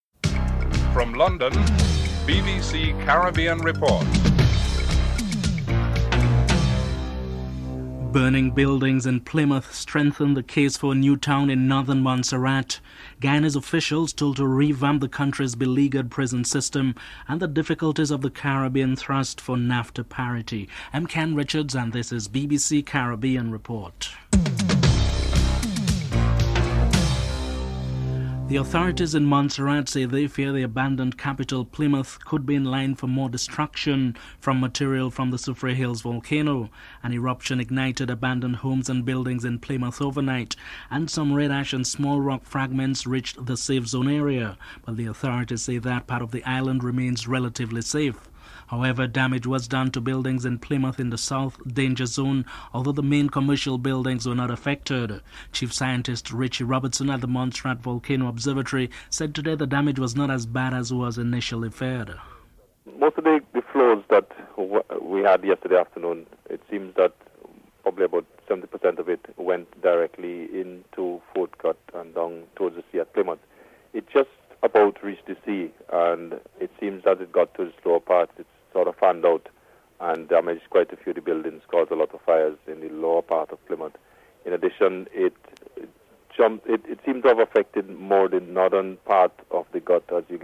Jamaica's High Commissioner in London, Derrick Heaven is interviewed (14:27-15:26)